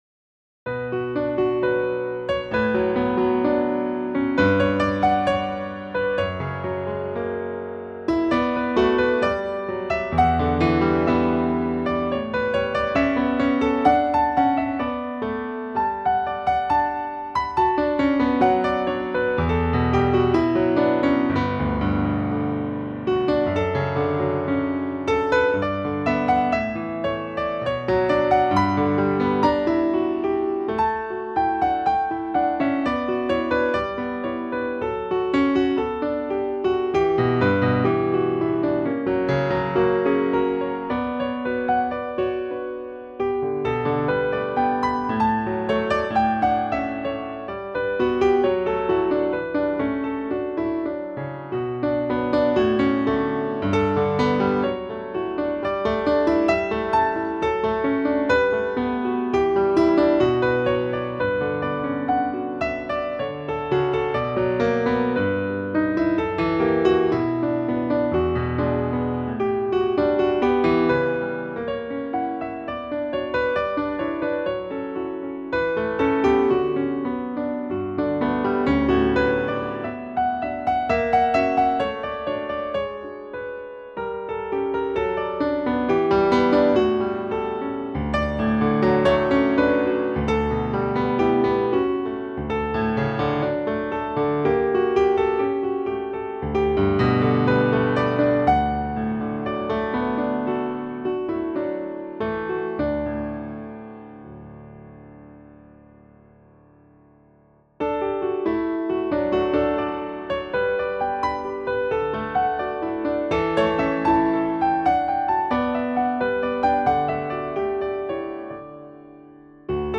Piano Sonatinas Audio Gallery
Grotrian 275 Concert Grand Piano